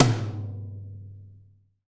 TOM TOM200QL.wav